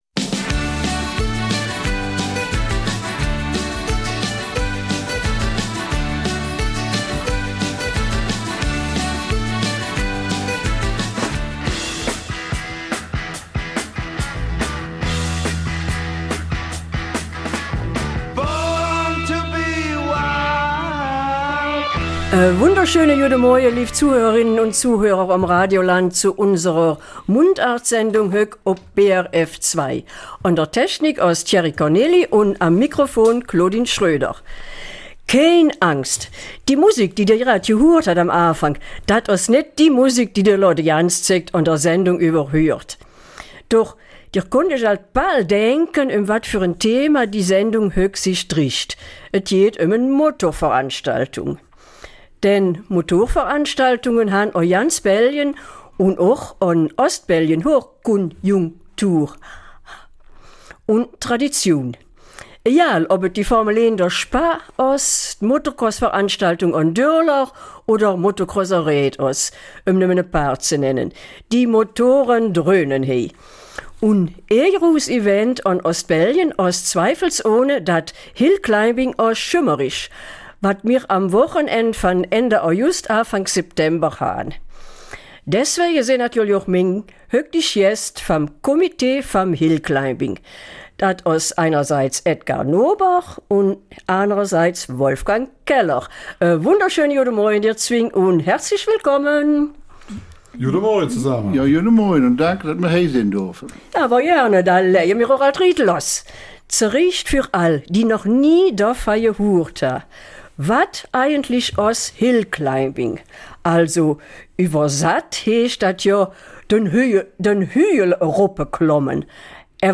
Eifeler Mundart - 24. August